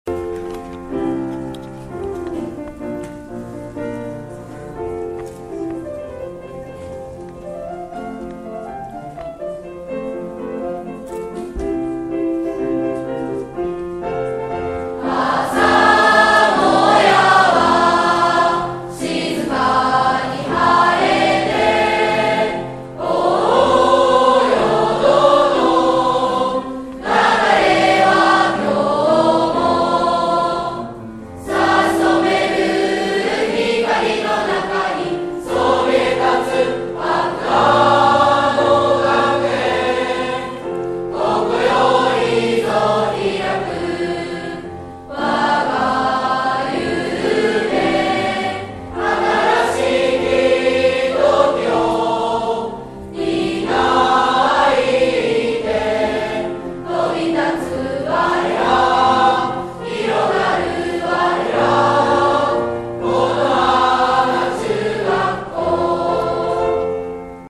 新1年生の新たなスタートを後押しした 新2・3年生の元気な校歌をどうそ!! 第56回入学式 校歌